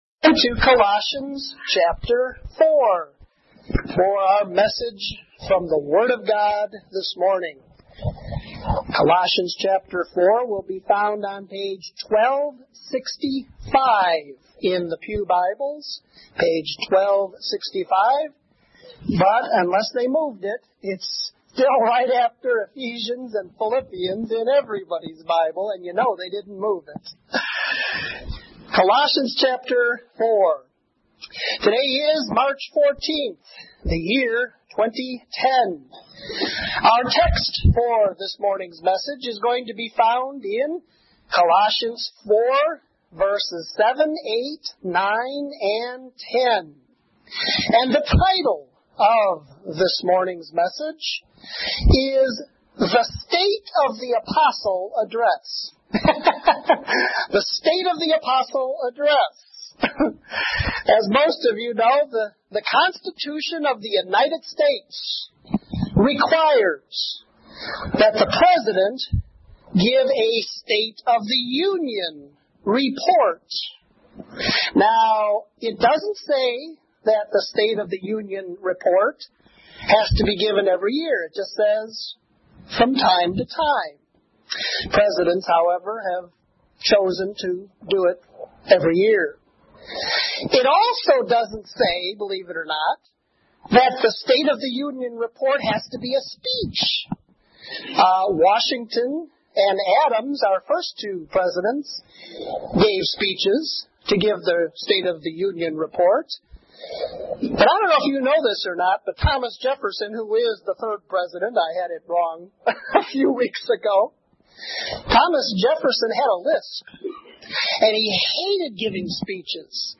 Lesson 45: Colossians 4:7-10